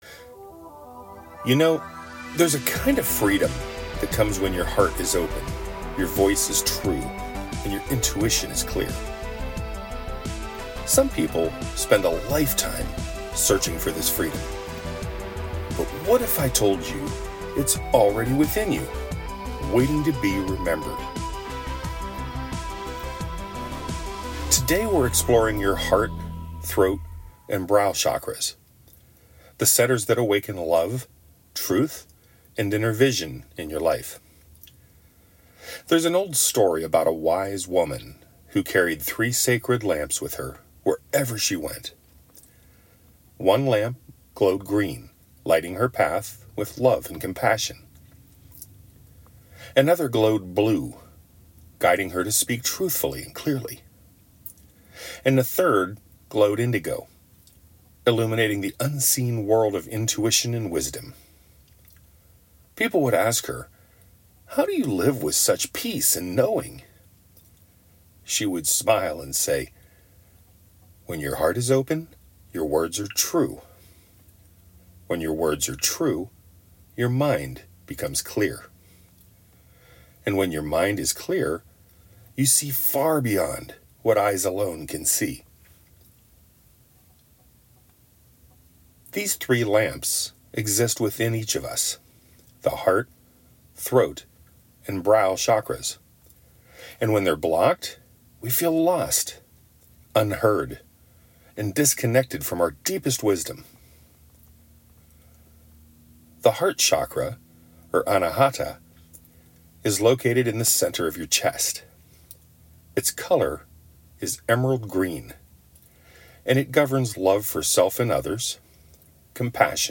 This lesson doesn’t include any meditations or healing work.